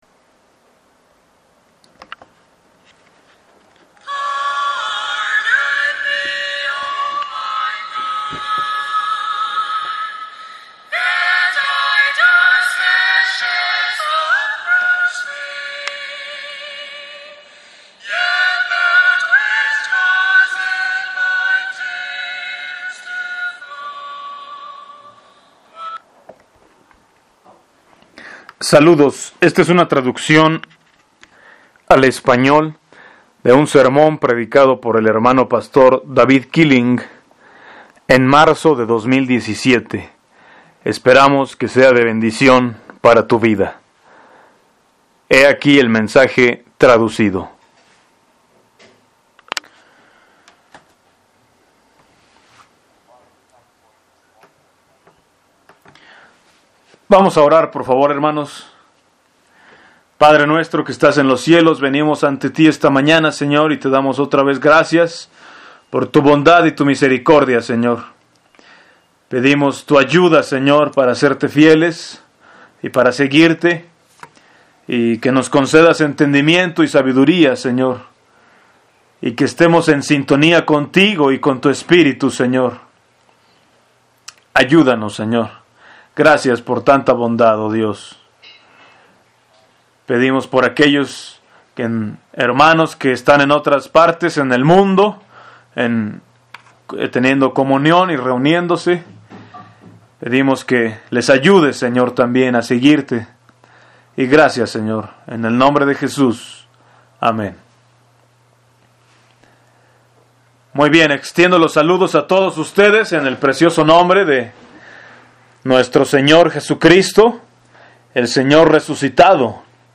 Para bajar a tu computadora o a otro dispositivo, haz click derecho en el t�tulo del serm�n, y luego selecciona �guardar como� (Internet explorer) o �guardar link como� (Netscape) Cristo el Victorioso